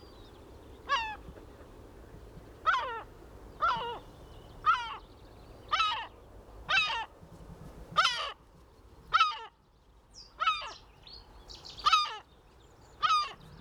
Vocalizations: What Do Baby Seagulls Sound Like?
Gull chicks emit a high-pitched “peep” or squeaky “eeep-eeep” to communicate hunger or alert distress.